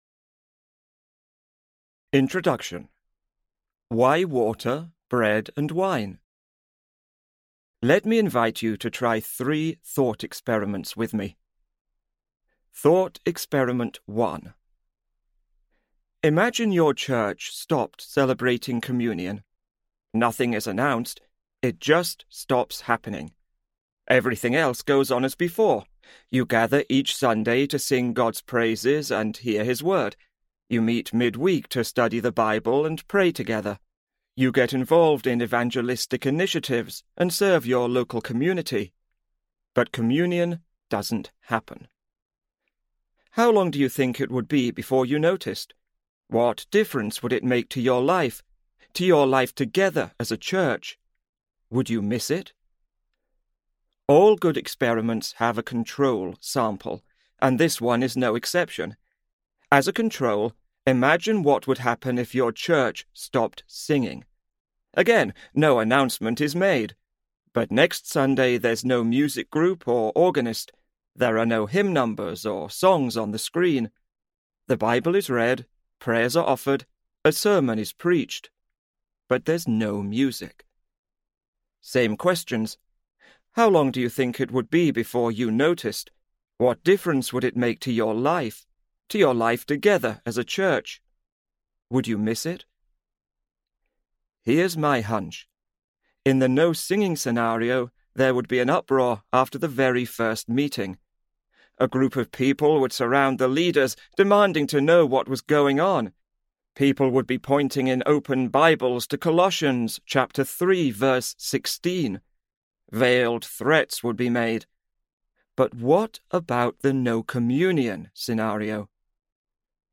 Truth We Can Touch Audiobook
Narrator
5.6 Hrs. – Unabridged